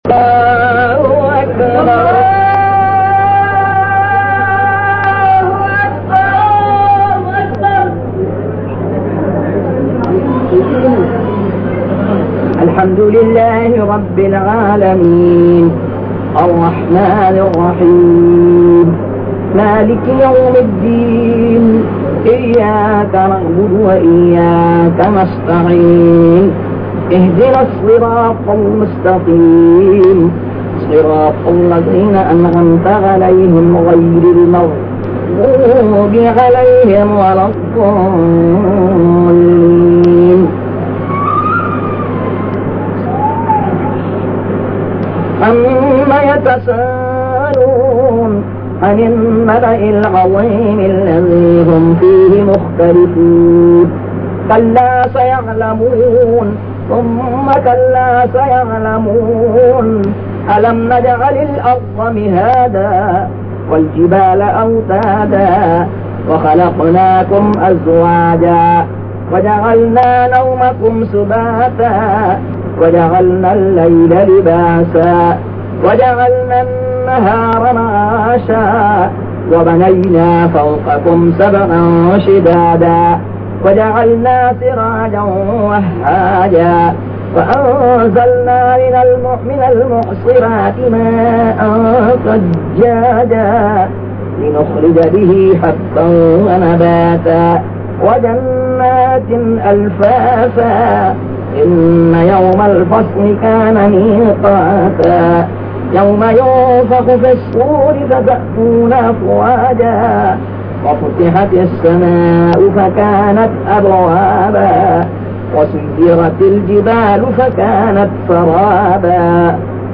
نام جماعت جمعہ زینت المساجد موضوع تقاریر آواز تاج الشریعہ مفتی اختر رضا خان ازہری زبان اُردو اُردو کل نتائج 861 قسم آڈیو ڈاؤن لوڈ MP 3 ڈاؤن لوڈ MP 4